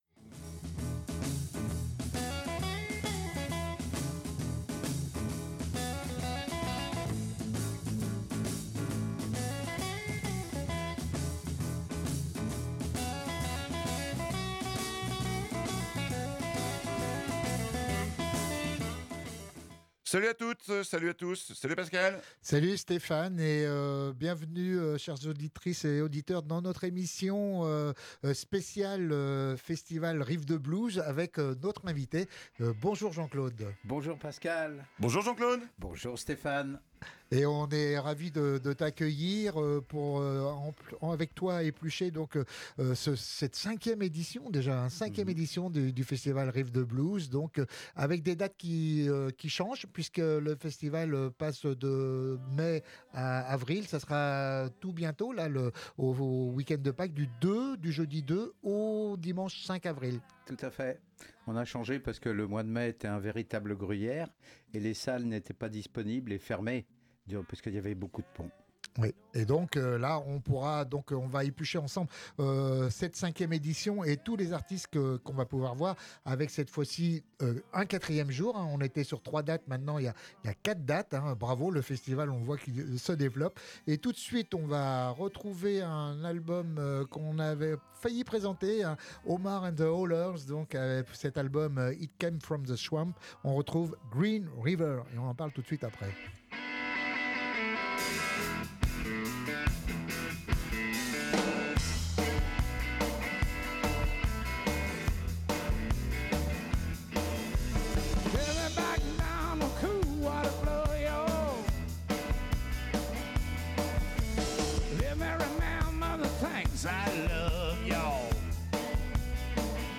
BLUES BLUES ROCK